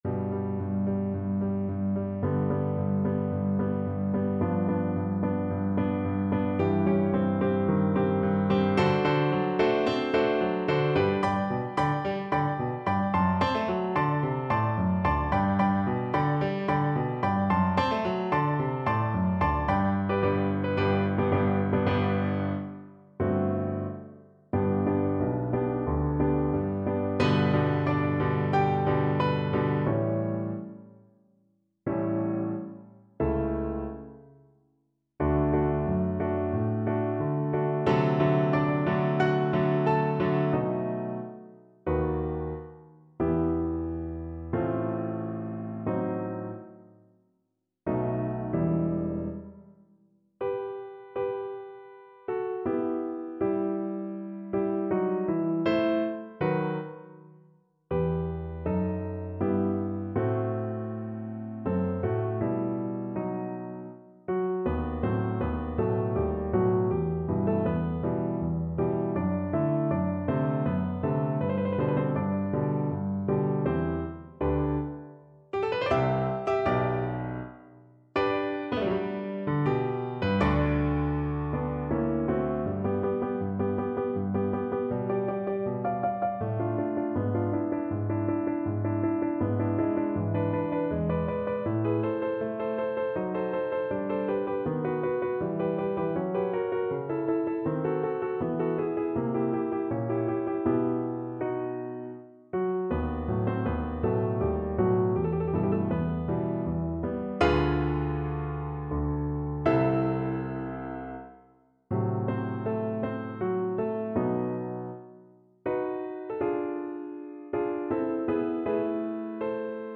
Classical Mozart, Wolfgang Amadeus O zittre nicht mein lieber Sohn from The Magic Flute Cello version
Play (or use space bar on your keyboard) Pause Music Playalong - Piano Accompaniment Playalong Band Accompaniment not yet available transpose reset tempo print settings full screen
Cello
G major (Sounding Pitch) (View more G major Music for Cello )
Allegro maestoso =110 (View more music marked Allegro)
4/4 (View more 4/4 Music)
Classical (View more Classical Cello Music)